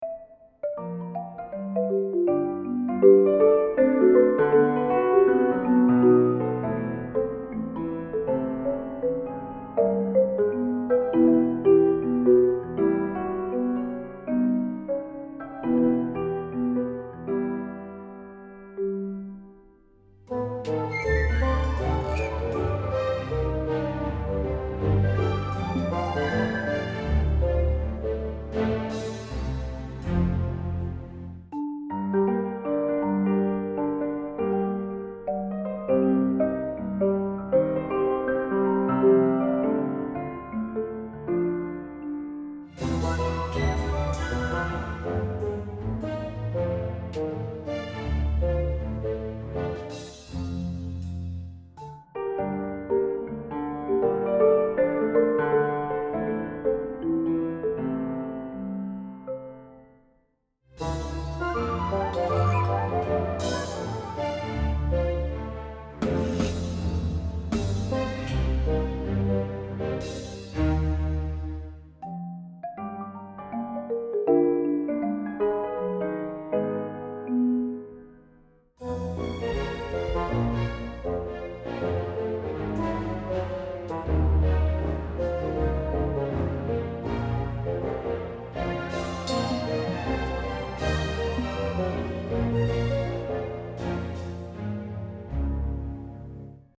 Orchestra & Combo
Orchestra & Soloists
Flute, Bassoon, Plucked Bass, Percussion
Piano